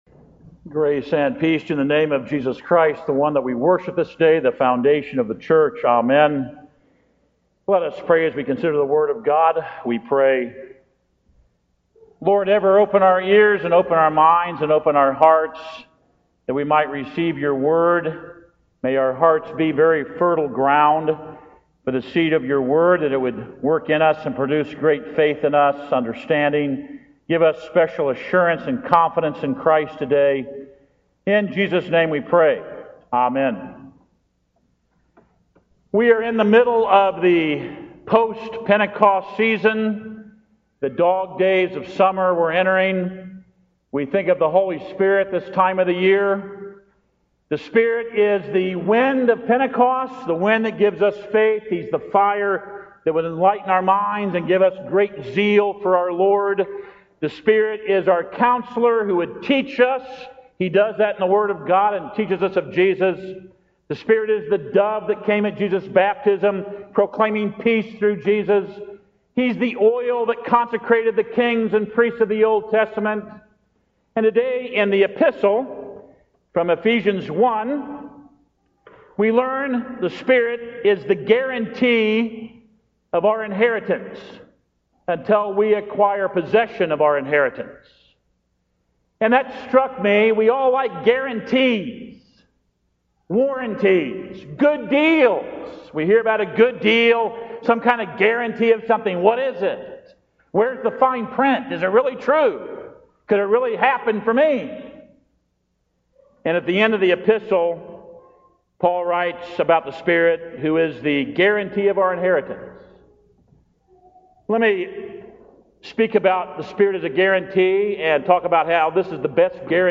Ephesians 1:3-14 Audio Sermon